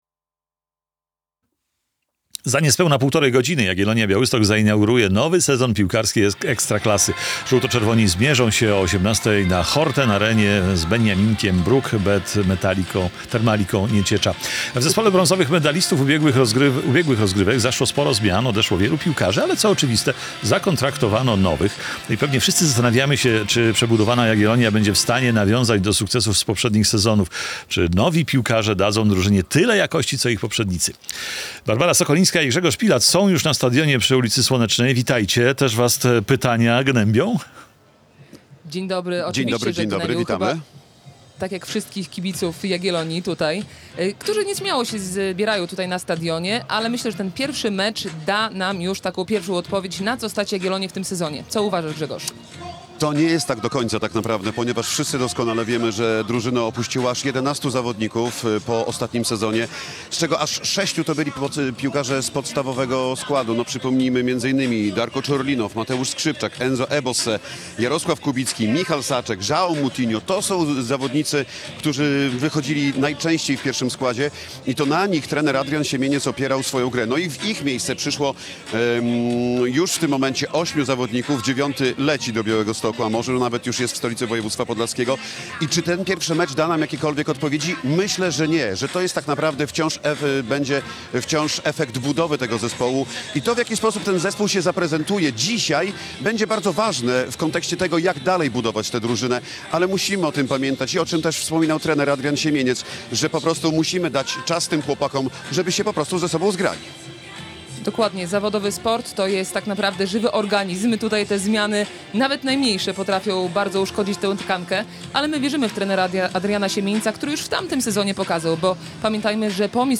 Czy nowi piłkarze dadzą Jagiellonii Białystok tyle jakości, co ich poprzednicy? - relacja